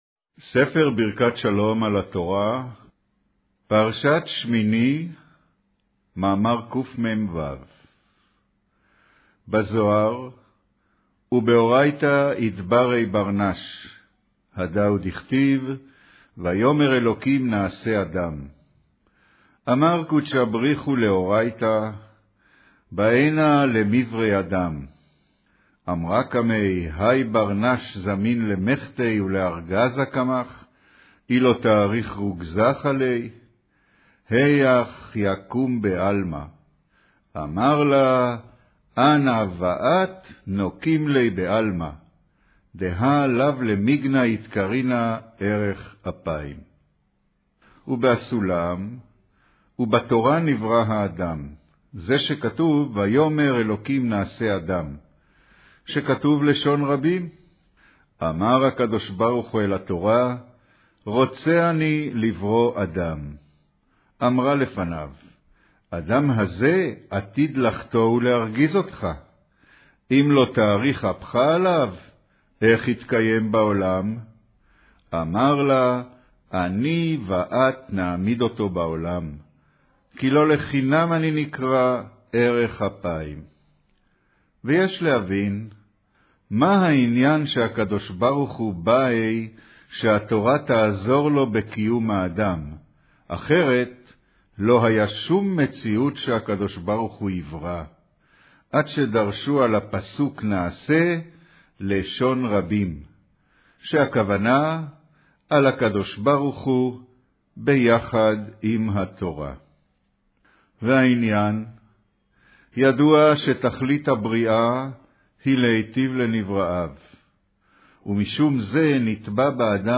אודיו - קריינות פרשת שמיני, מאמר ובאורייתא אתברי בר נש